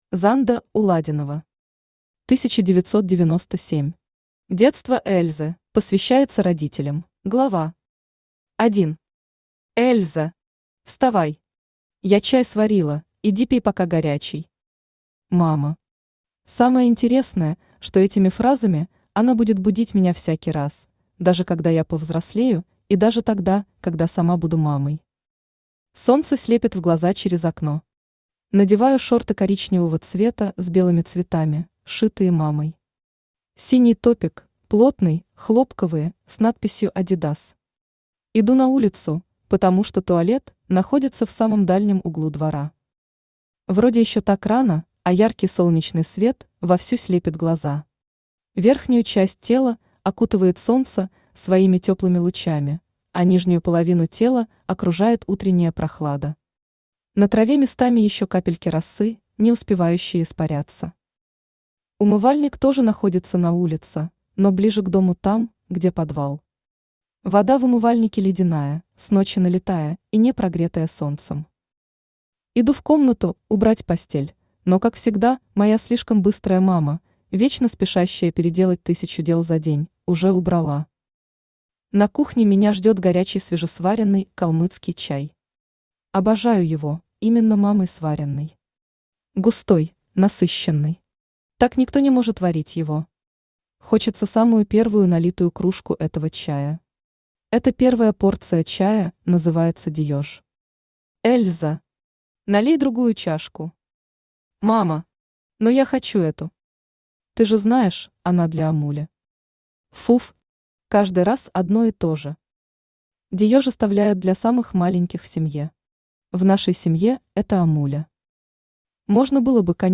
Аудиокнига 1997. Детство Эльзы | Библиотека аудиокниг
Детство Эльзы Автор Занда Анатольевна Уладинова Читает аудиокнигу Авточтец ЛитРес.